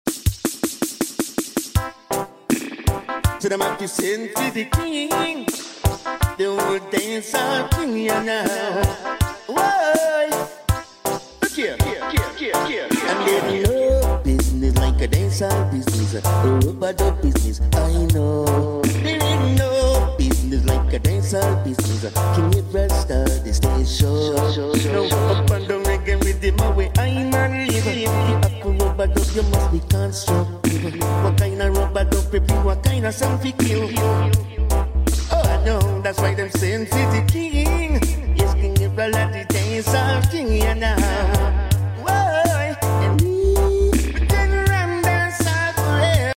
Best rellay amplifier with silent